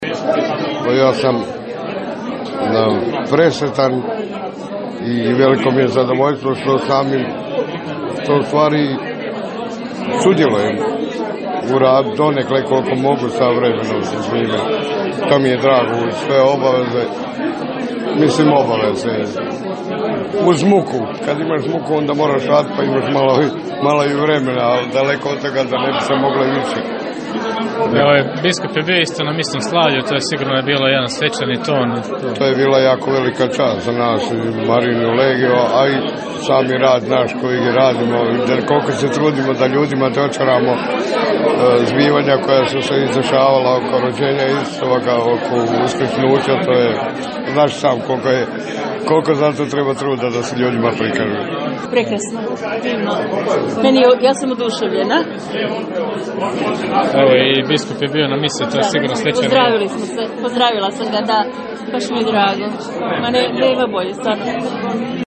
Nakon misnog slavlja uslijedio je dokumentarni film o Marijinoj legiji nakon kojeg su se prisutni okupili u Pastoralnom centru na druženju uz kola?e i sokove. Okupili su se i brojni prijatelji Marijine legije i molitveni ?lanovi koji sudjeluju u Jaslicama i Pasiji.